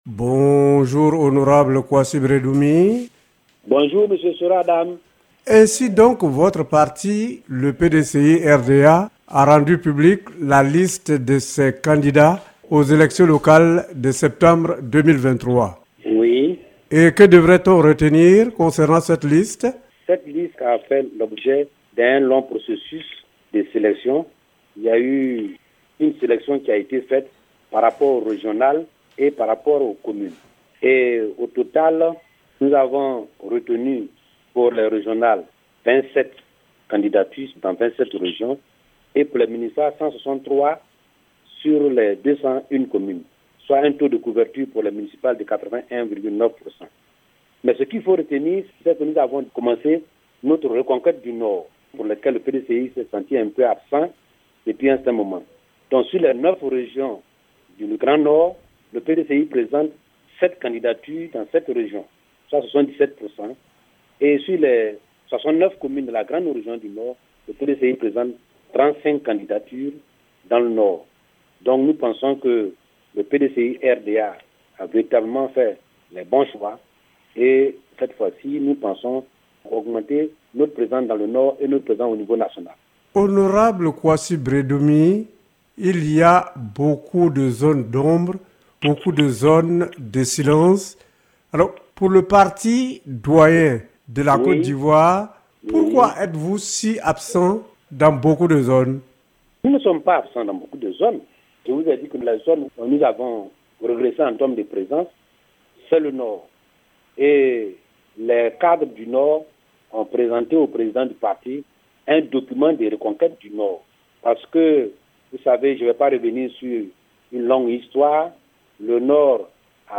L’invité de la rédaction de ce jour est le porte-parole du PDCI-RDA. Pour lui, c’est après une analyse minutieuse que la liste de ses candidats pour les municipales et régionales de septembre 2023 a été récemment publiée ajoutant que son parti entend refleurir dans le nord du pays à travers ces élections.